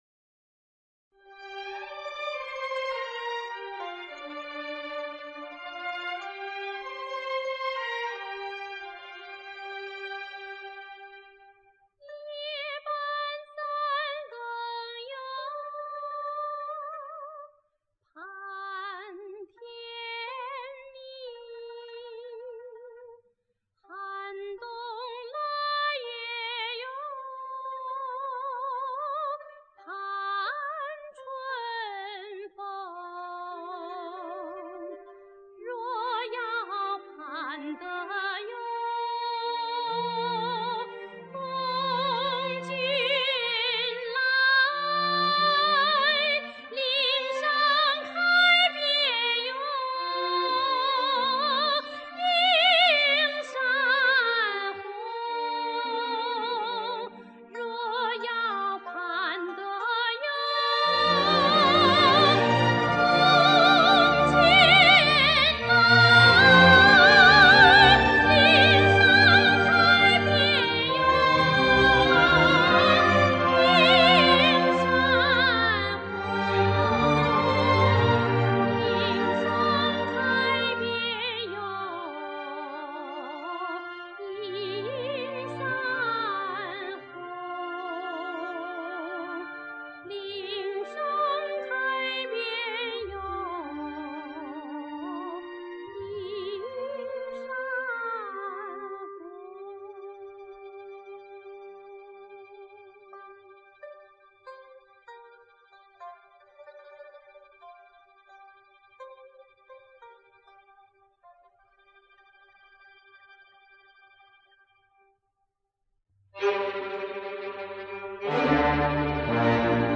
插曲